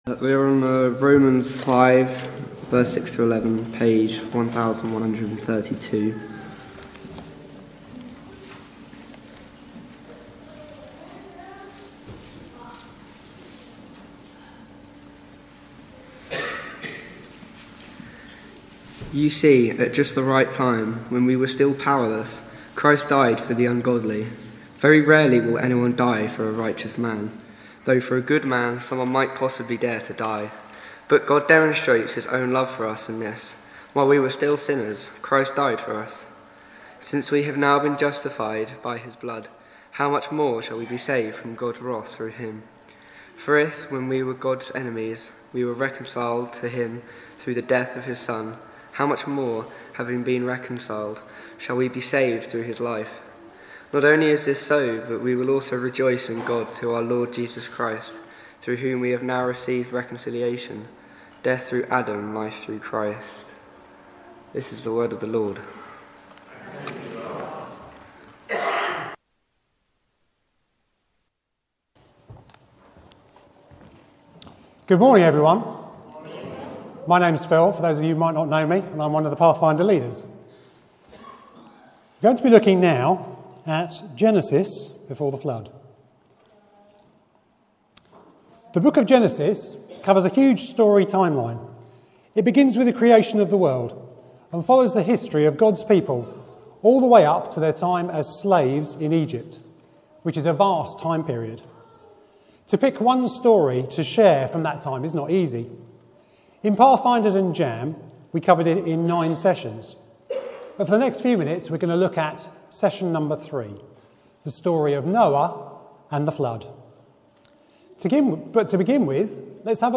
All Age Pathfinder Service
Pathfinders Passage: Romans 5:6-11 Service Type: Sunday Morning « New Year Essentials